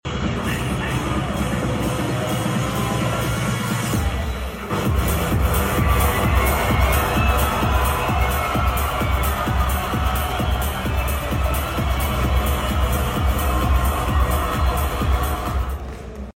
Fragment of my skit at the Poznan Game Arena cosplay contest xDDD